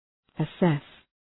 Shkrimi fonetik {ə’ses}